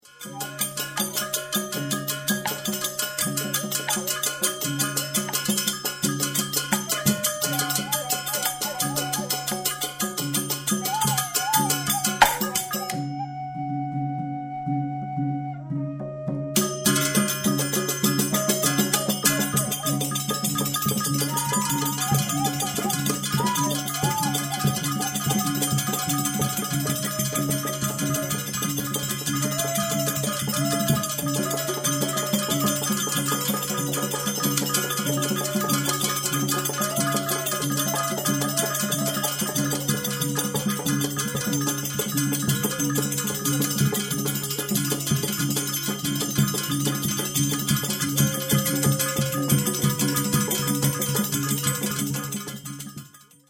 shadow puppet music